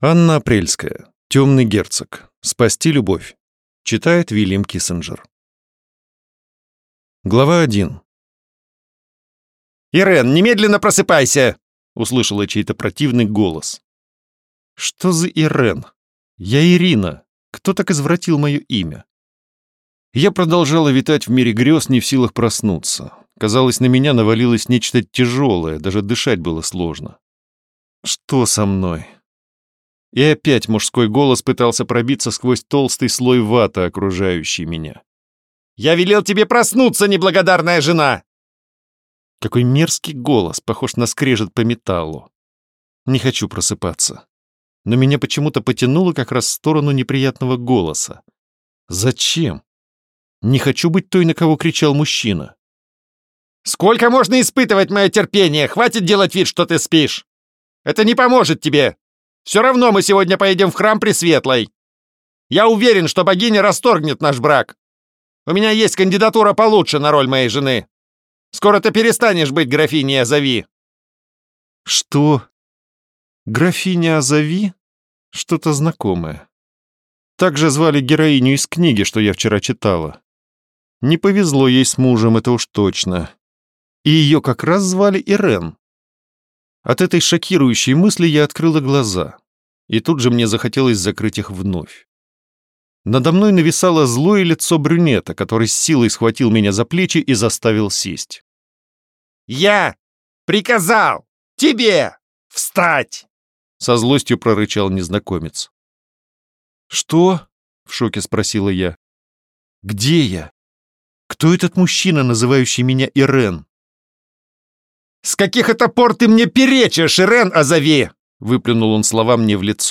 Аудиокнига Темный герцог. Спасти любовь | Библиотека аудиокниг
Прослушать и бесплатно скачать фрагмент аудиокниги